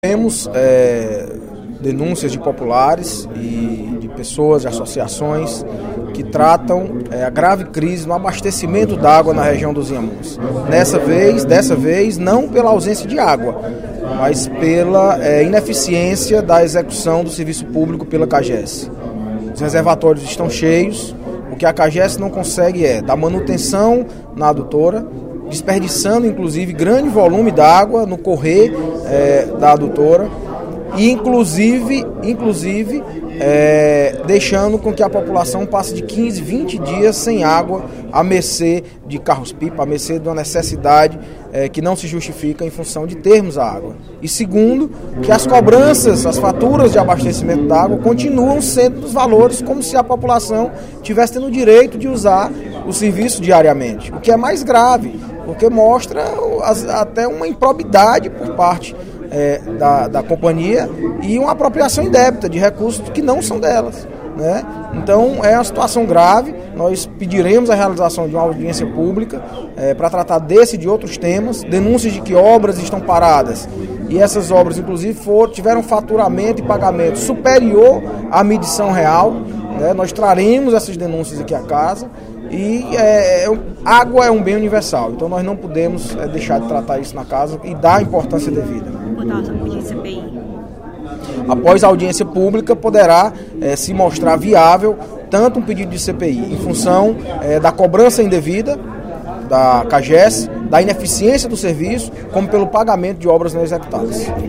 O deputado Audic Mota (PMDB) denunciou, nesta quinta-feira (09/06), no primeiro expediente da sessão plenária, a crise de abastecimento de água na região dos Inhamuns.